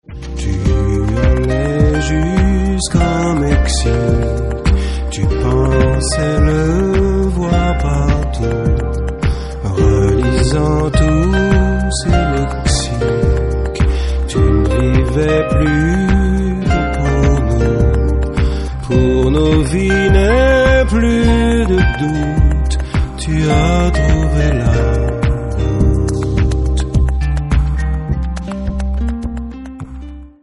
MP3 64kbps-Stereo